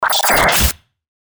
FX-1594-BREAKER
FX-1594-BREAKER.mp3